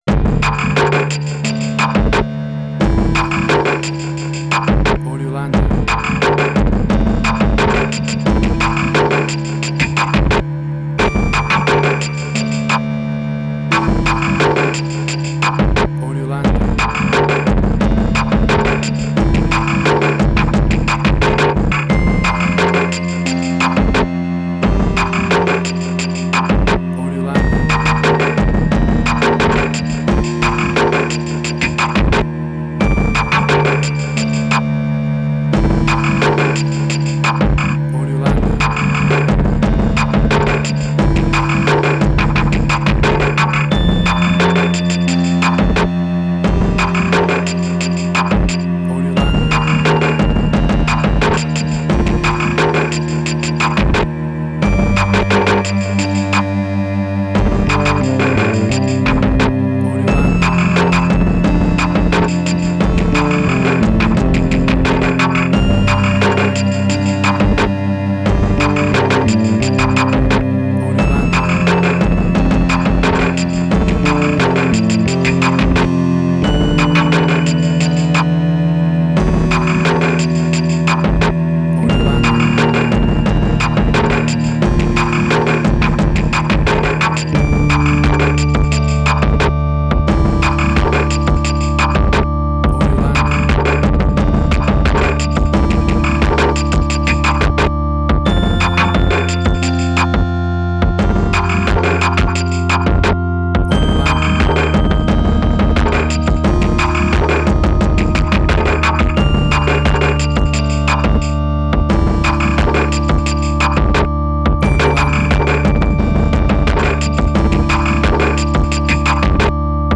IDM, Glitch.
emotional music
Tempo (BPM): 88